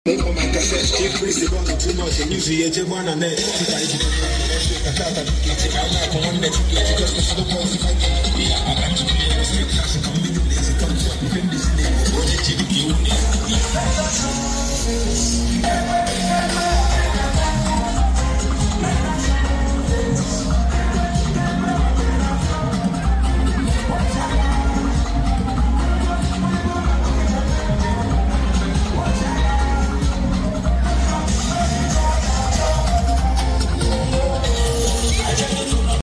🎓 Graduation or festival?